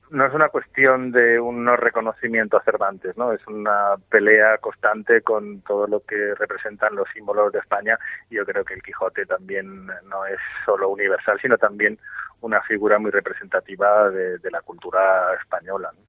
Paco Sierra, portavoz de la formación naranja, explica el porque del rechazo de la escultura de Cervantes